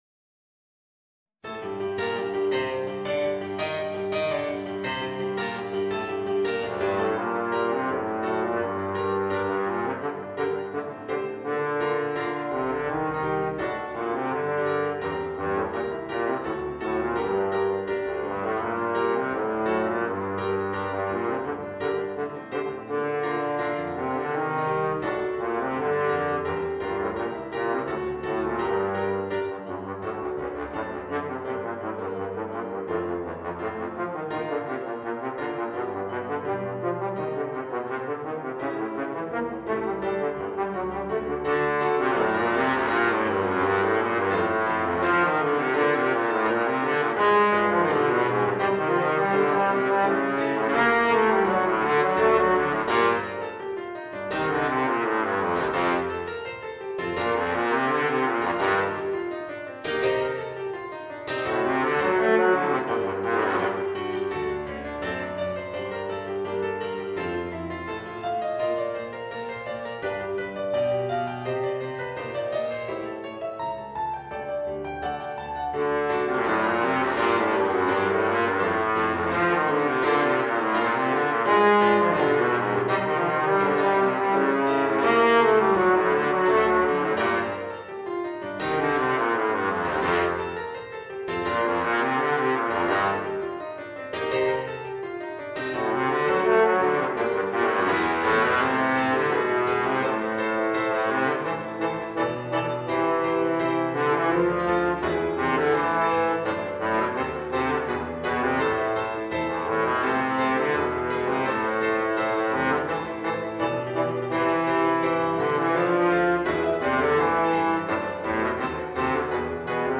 Voicing: Bass Trombone and Piano